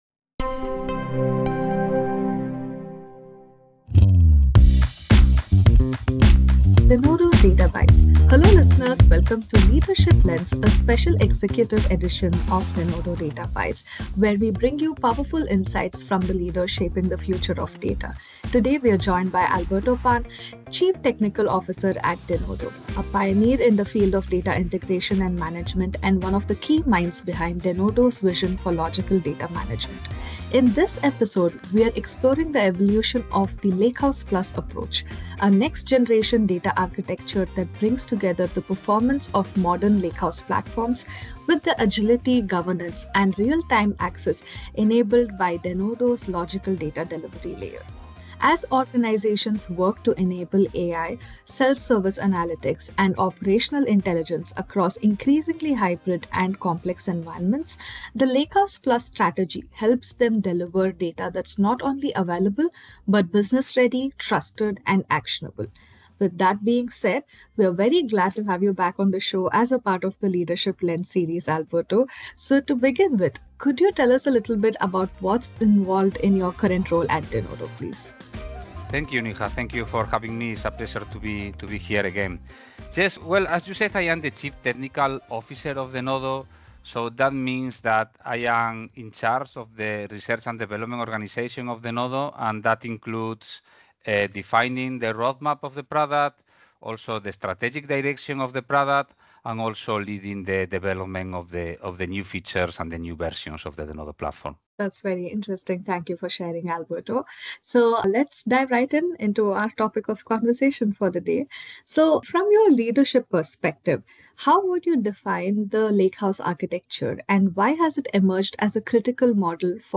Welcome to the Denodo Data Bites Podcast! We’re excited to bring you a brand-new episode of Leadership Lens, our special executive series where we sit down with the visionaries shaping the future of data.